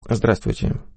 • Качество: высокое
Владимир Путин приветствует всех